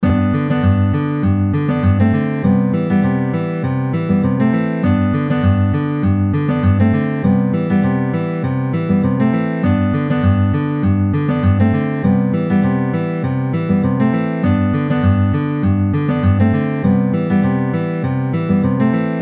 EXAMPLE 5 Riff